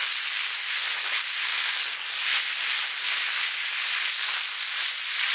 small_wheel_22KHz.wav